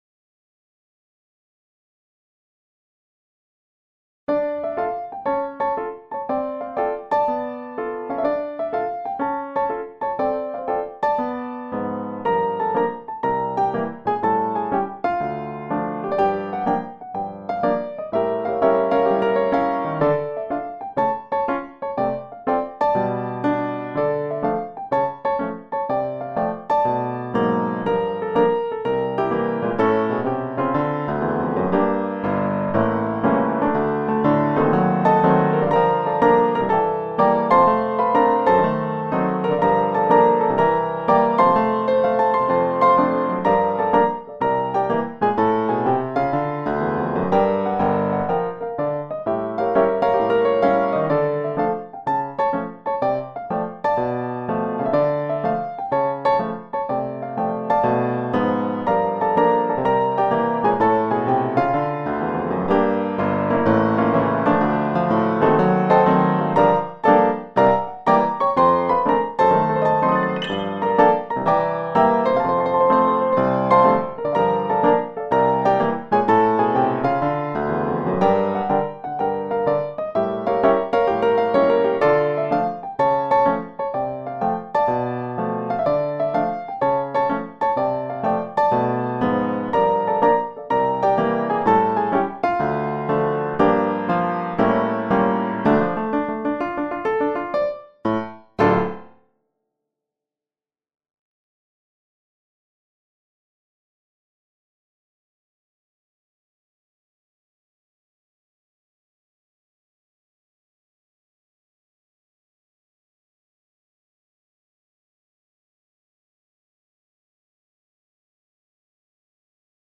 Dafür bekommt man ein Klavier, das aus verschiedenen Klavieren physikalisch modelliert wurde und das mit einer ganzen Reihe von Presets ausgestattet ist.
Neben den schon reichlich vorhandenen Klangbeispielen auf der Modartt Webseite (siehe Link weiter oben), habe ich hier noch ein paar Stücke mit verschiedenen Presets aufgenommen:
Die MIDI Dateien wurden mir von Modartt zur Verfügung gestellt.